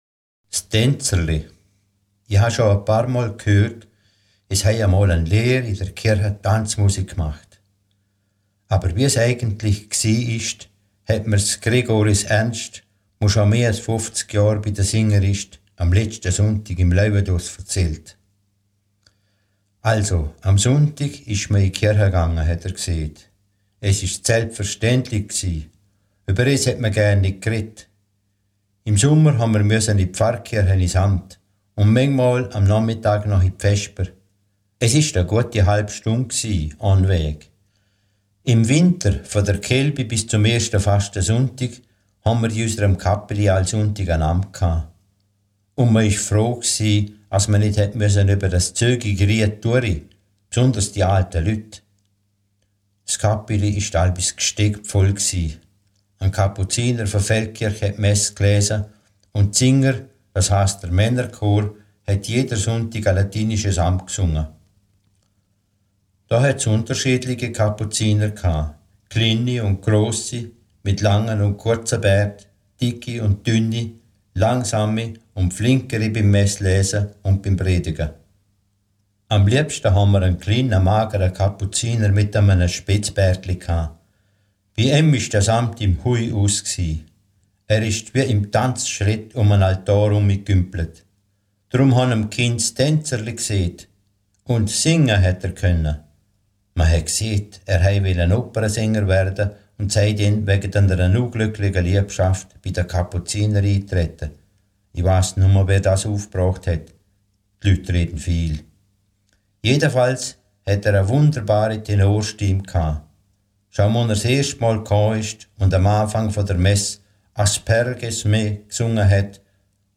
S'Tenzerle.mp3 Farbige Geschichte Liechtenstein Kontakt Mitglied werden Geschichten in Mundart Sektionen